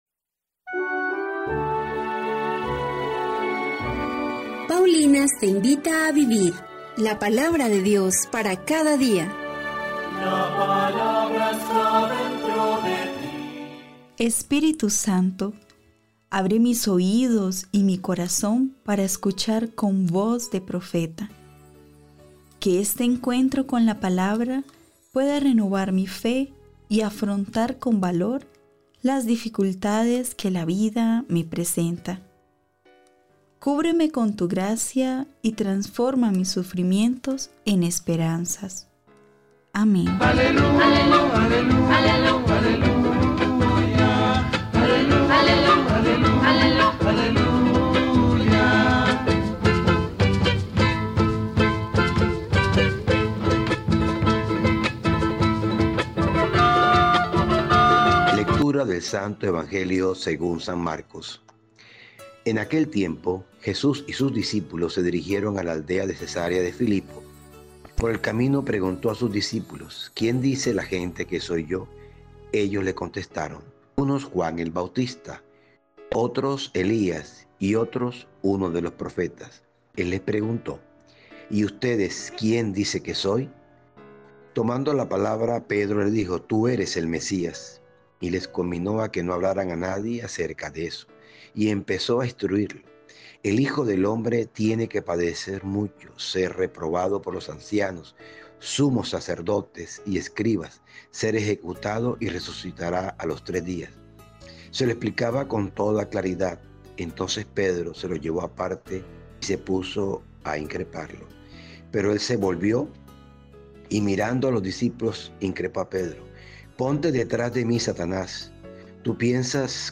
Lectura de la Carta a los Hebreos 5, 7-9